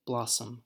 //ˈblɑ.səm//
blossom-us.mp3